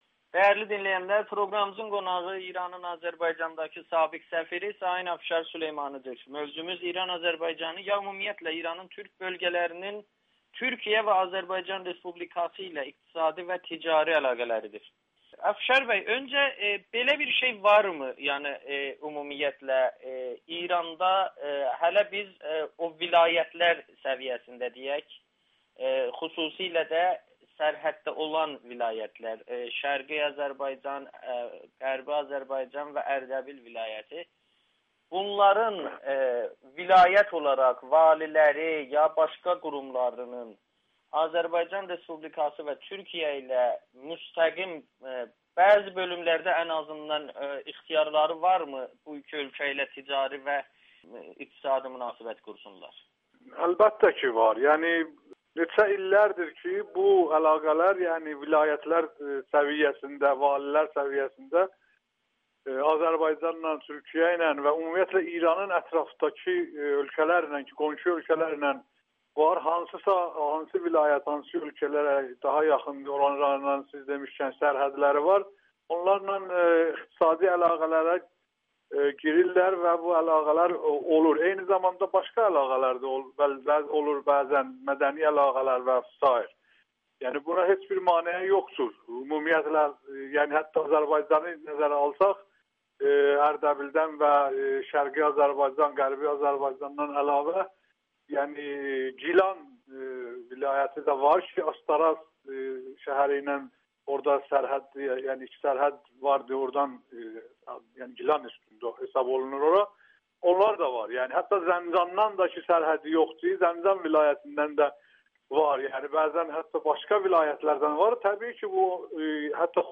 Afşar Süleymani: Türk Fraksiyasi Türkiyə və Azərbaycanla münasibətlərə müsbət təsir buraxa bilər [Audio-Müsahibə]
Afşar Süleymani Amerikanın Səsinə danışır